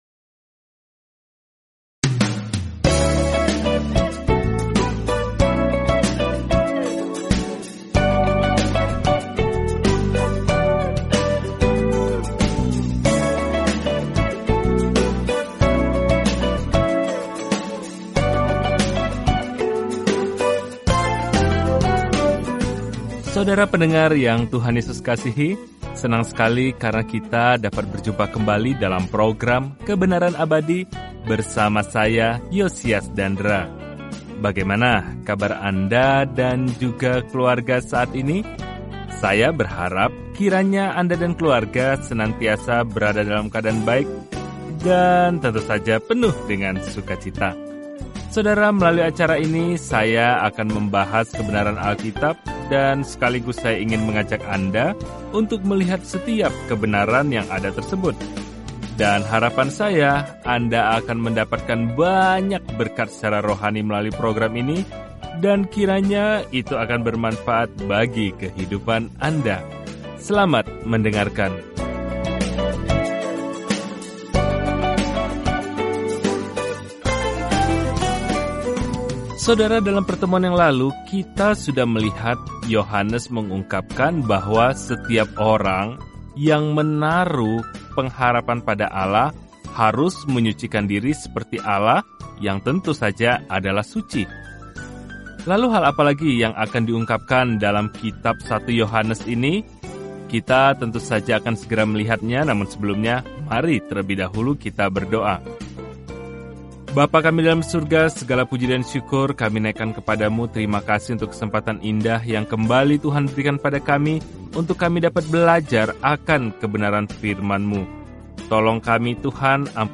Firman Tuhan, Alkitab 1 Yohanes 3:4-7 Hari 13 Mulai Rencana ini Hari 15 Tentang Rencana ini Tidak ada jalan tengah dalam surat pertama Yohanes ini – kita memilih terang atau gelap, kebenaran daripada kebohongan, cinta atau benci; kita menganut salah satunya, sama seperti kita percaya atau menyangkal Tuhan Yesus Kristus. Telusuri 1 Yohanes setiap hari sambil mendengarkan pelajaran audio dan membaca ayat-ayat tertentu dari firman Tuhan.